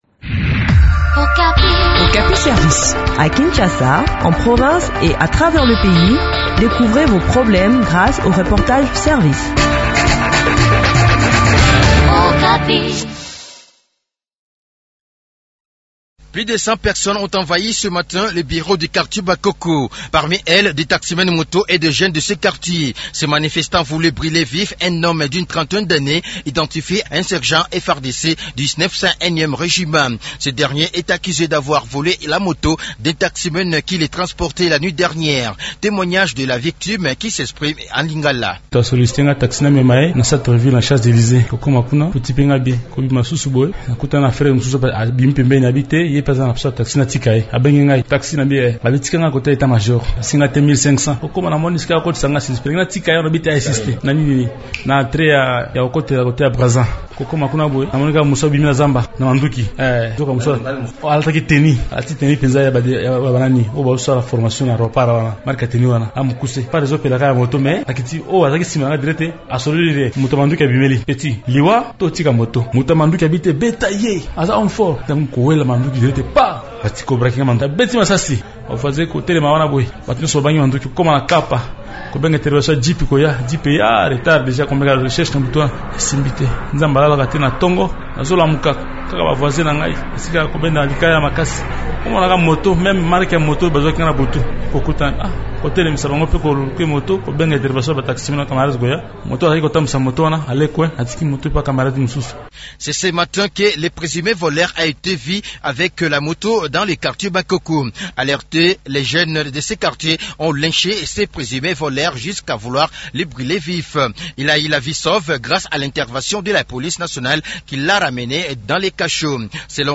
Le point de la situation sur terrain après cet incident dans cet entretien